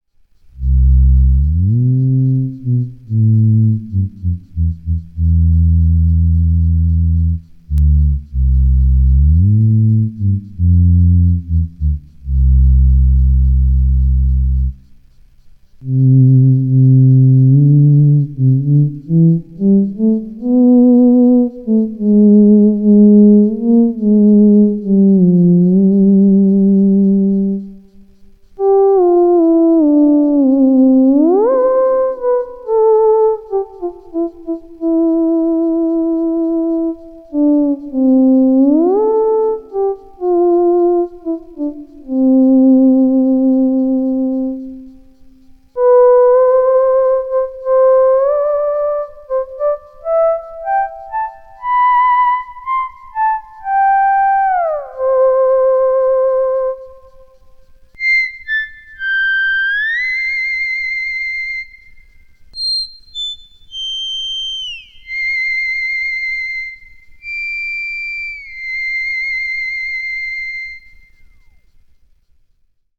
A demo of the latest hobbs_mid preset: